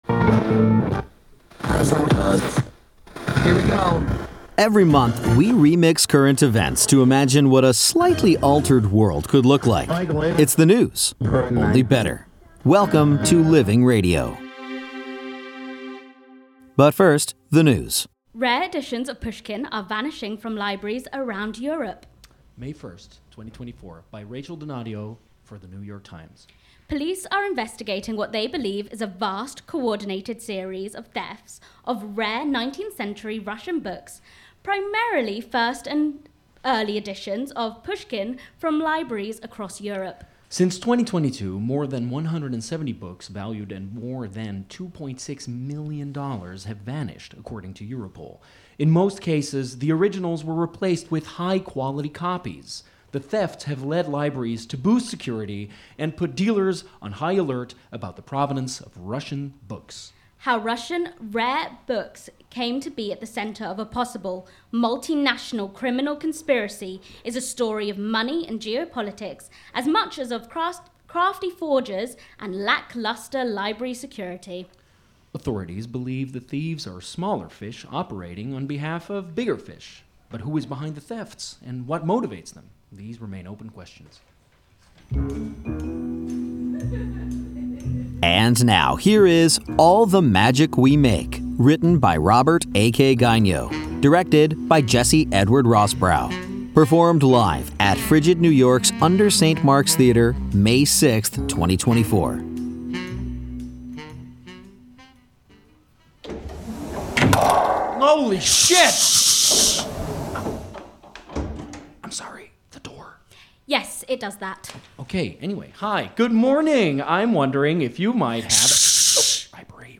performed live at FRIGID New York’s UNDER St. Mark’s Theater, May 6, 2024